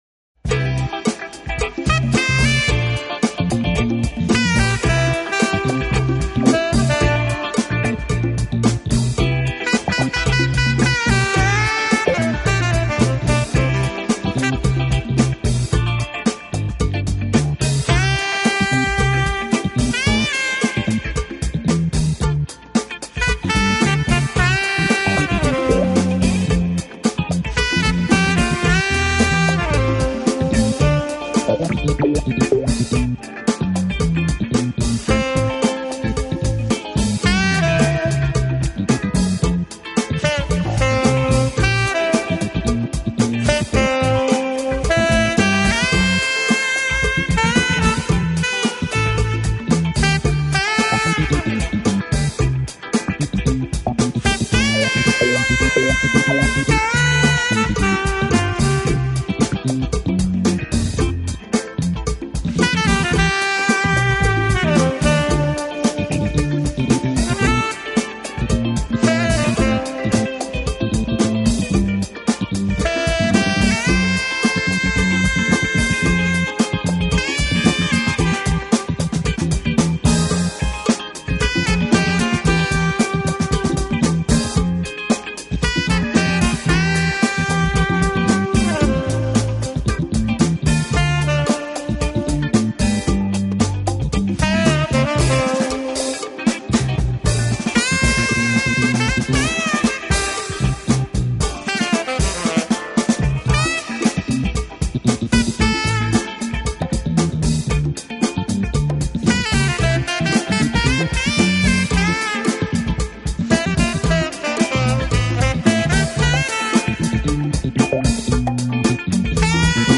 Subtitle: 4Cds of Essential Smooth Jazz